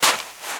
STEPS Sand, Walk 06.wav